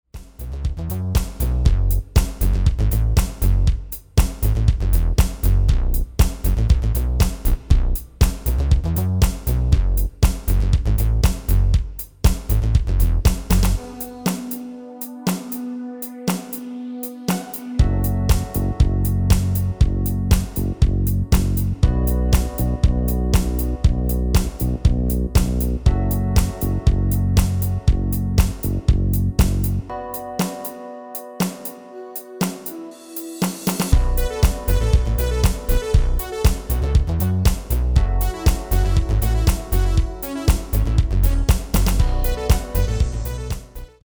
Demo/Koop midifile
Genre: Actuele hitlijsten
- GM = General Midi level 1
- Géén vocal harmony tracks